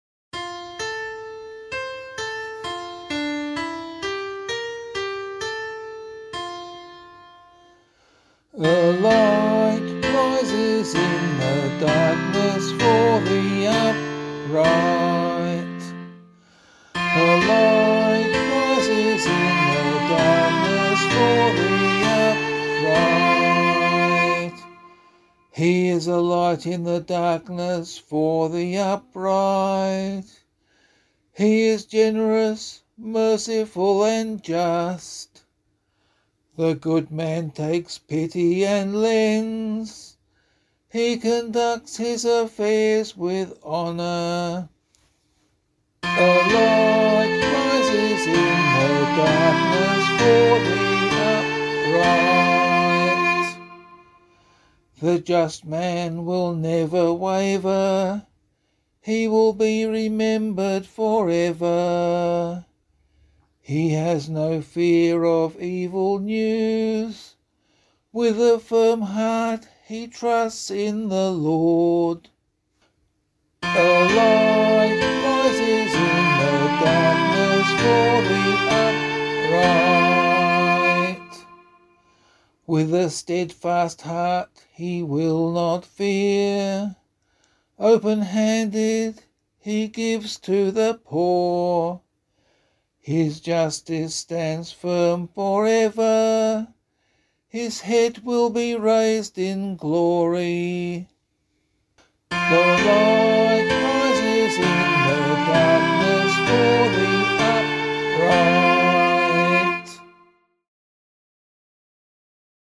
039 Ordinary Time 5 Psalm A [LiturgyShare 6 - Oz] - vocal.mp3